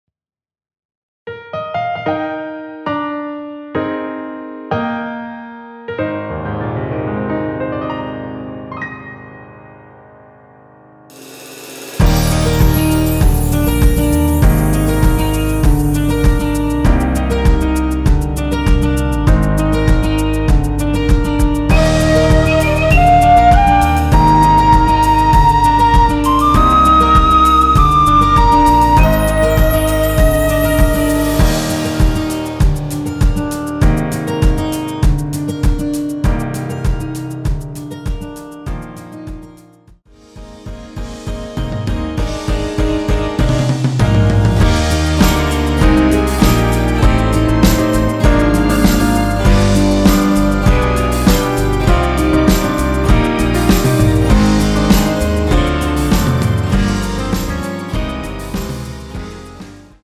원키에서(-5)내린 MR입니다.
Eb
앞부분30초, 뒷부분30초씩 편집해서 올려 드리고 있습니다.